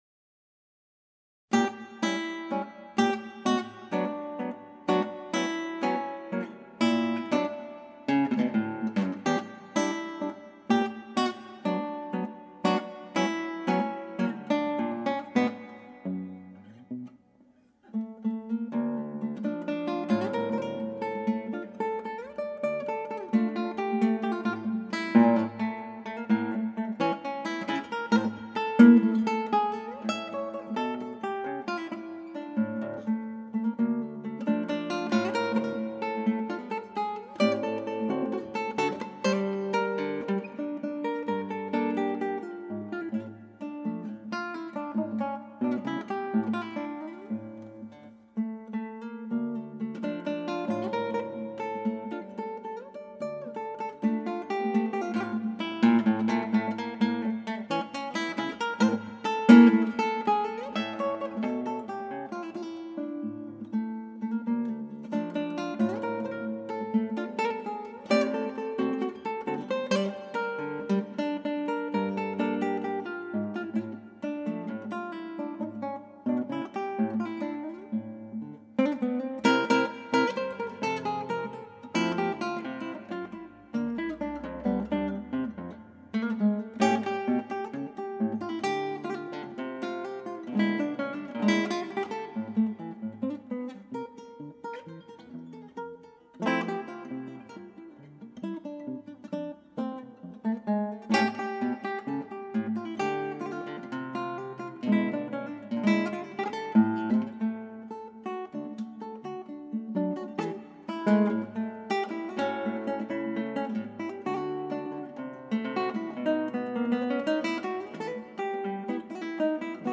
ギターの自演をストリーミングで提供